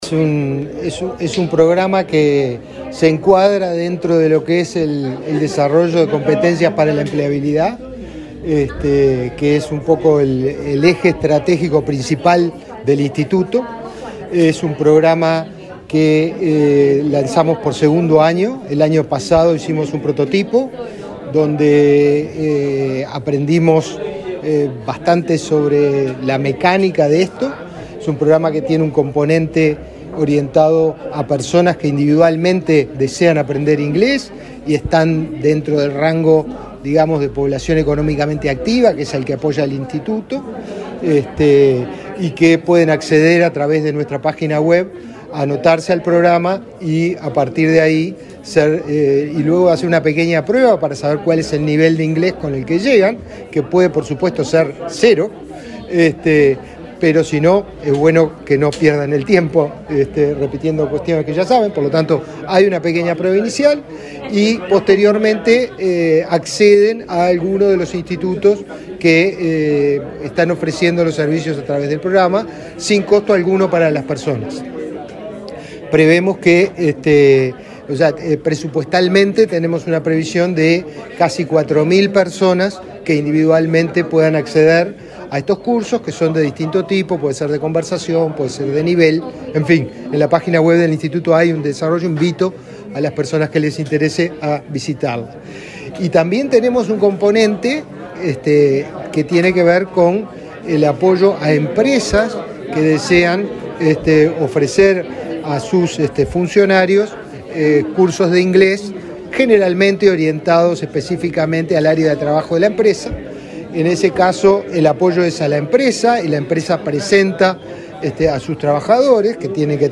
Entrevista al director general de Inefop, Pablo Darscht